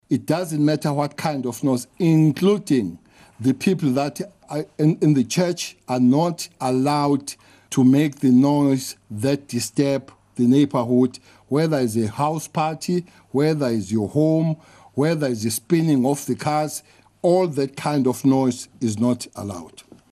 He briefed the media yesterday afternoon on law enforcement during lockdown level 1.